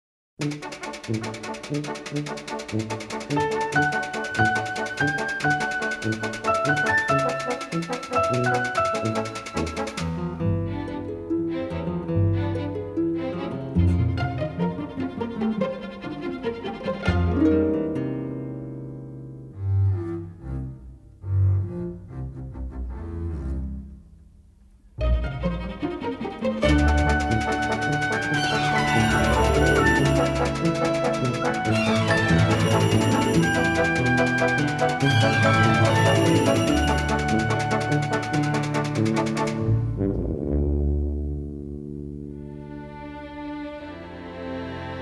The score is a sensitive gem of melody and Americana
remixed and mastered from the original 1/2" stereo tapes.